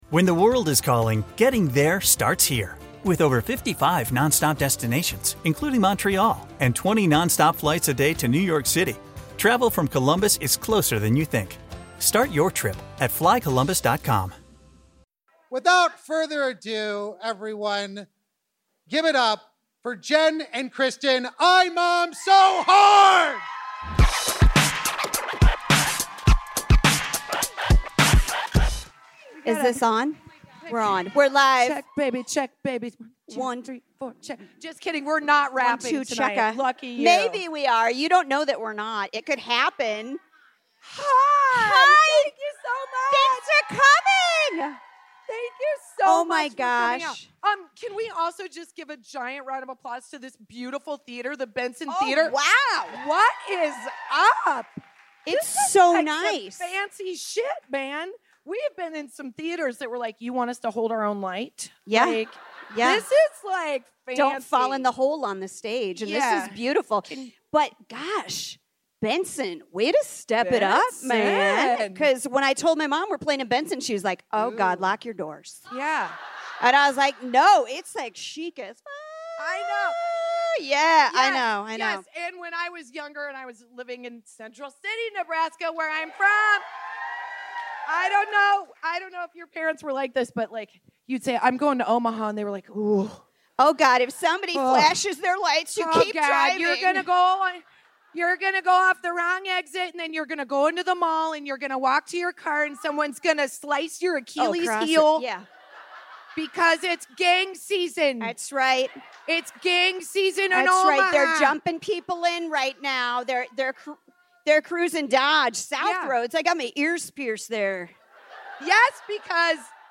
We could not have been more excited to record our very first LIVE PODCAST at the Omaha Comedy Festival in our home state of Nebraska! Today, we talk coo coo clocks, hip dysplasia, and take questions from the audience!